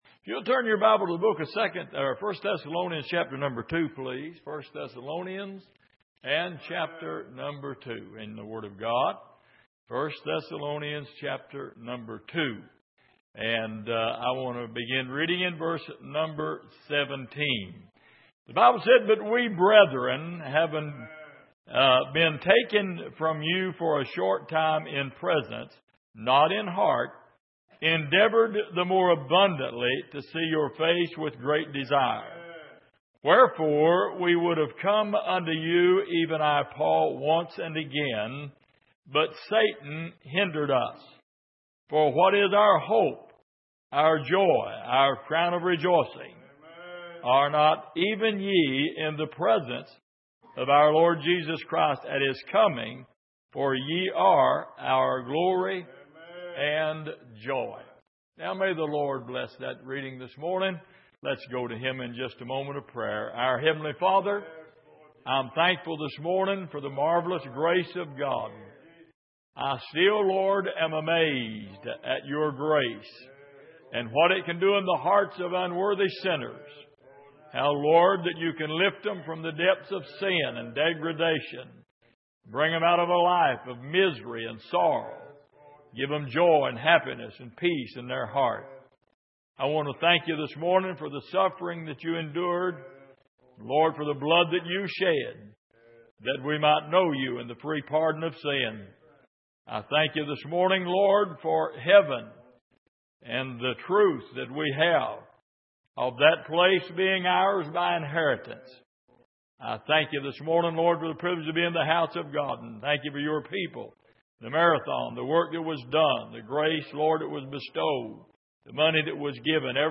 Miscellaneous Passage: 1 Thessalonians 2:17-20 Service: Sunday Morning Hindrances « Delivered From Destruction What’s Going On Between You And The Devil?